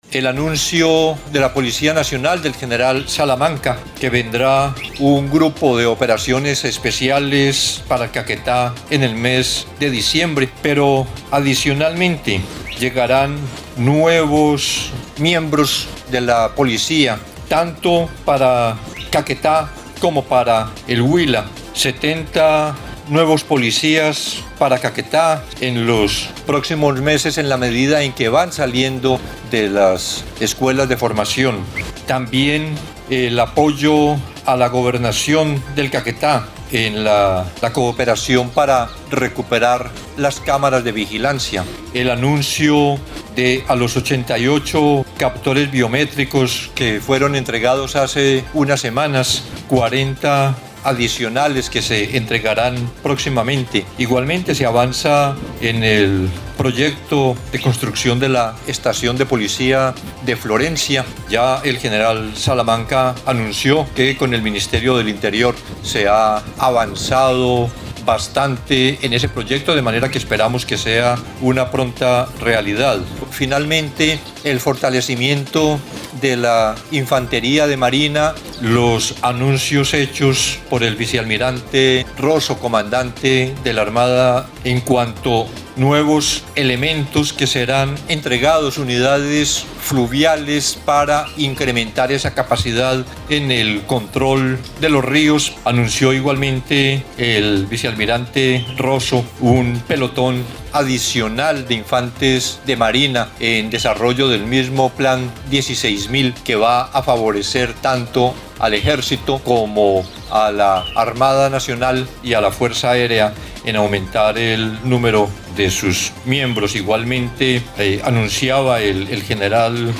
El anuncio fue hecho por el ministro de defensa, Iván Velásquez, quien dijo que, el refuerzo del pie de fuerza, se hará con la asignación de 70 policías adicionales y la incorporación de 200 soldados profesionales para la Fuerza Aérea, una medida inédita en la región.
01_MINDEFENZA_IVÁN_VELÁSQUEZ_ANUNCIOS.mp3